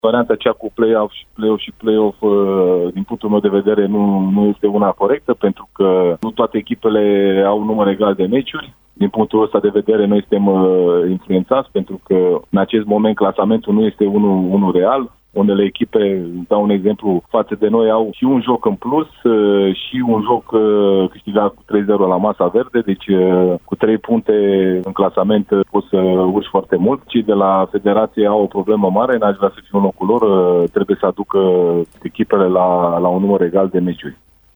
Cei doi tehnicieni, invitați azi la Arena Radio, au vorbit și despre stările de spirit ale loturilor pe care le conduc de la distanță: